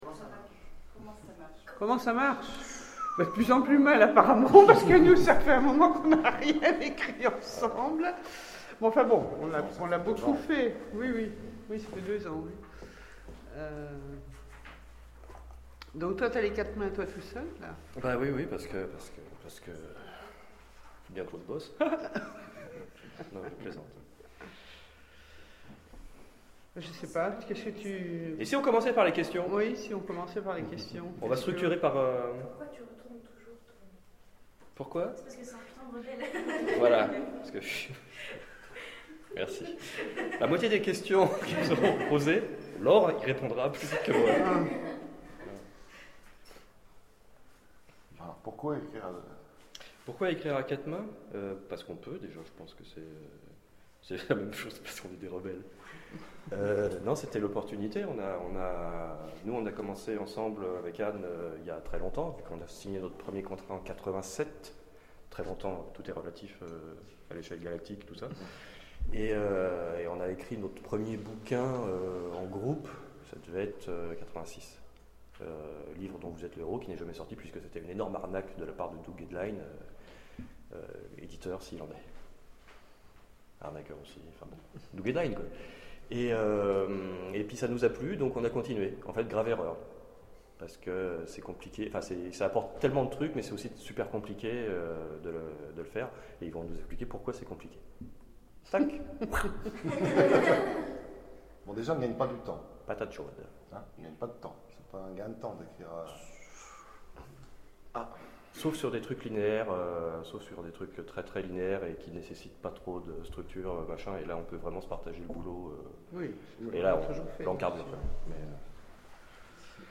Oniriques 2013 : Conférence Ecrire à quatre mains, comment ça marche ?